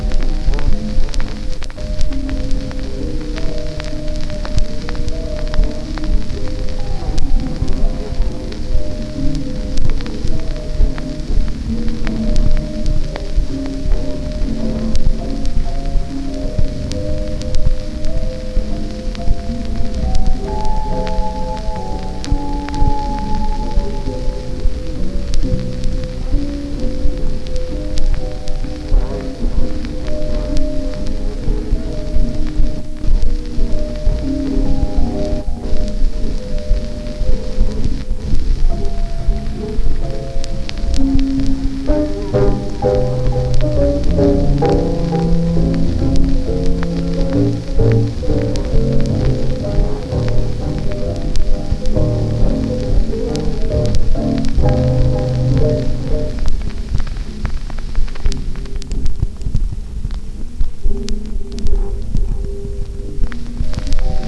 hotel.wav